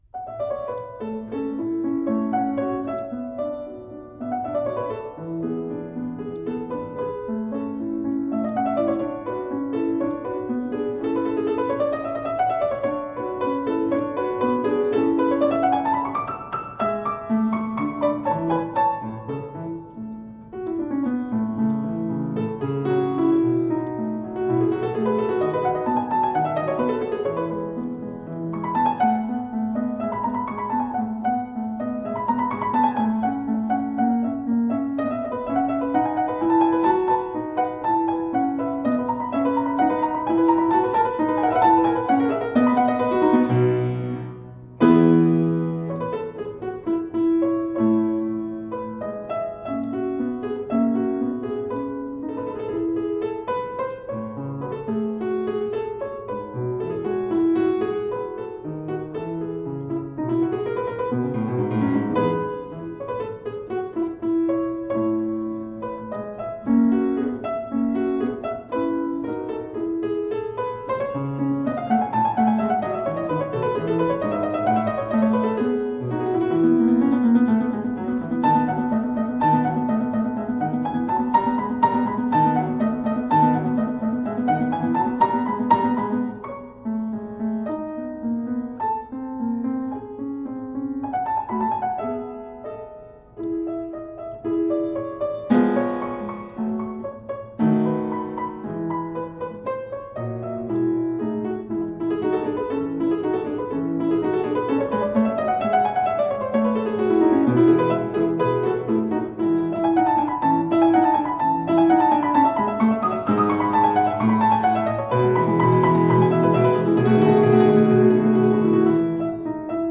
Fortepiano music from the early 1800s.